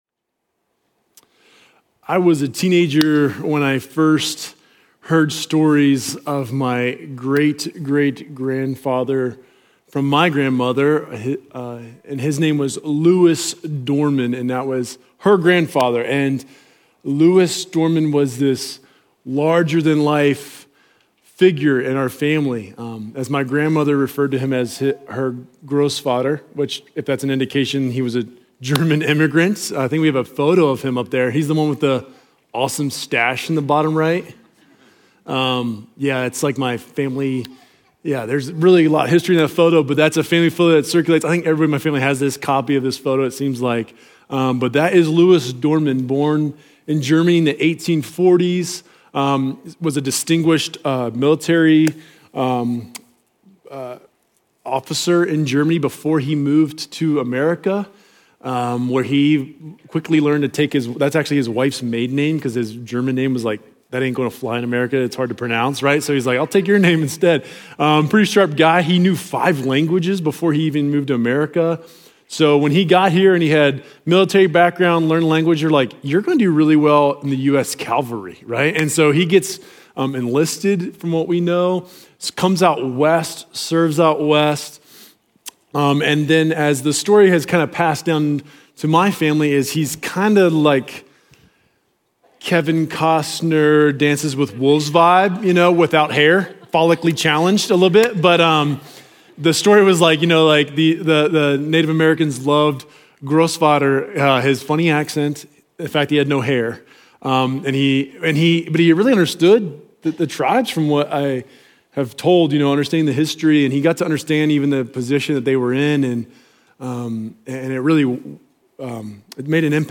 Journey Church Bozeman Sermons Book of Romans: Two Adams, Two Inheritances Mar 09 2025 | 00:36:30 1x 00:00 / 00:36:30 Subscribe Share Apple Podcasts Overcast RSS Feed Share Link Embed